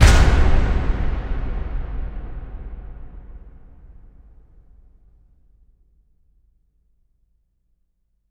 LC IMP SLAM 1A.WAV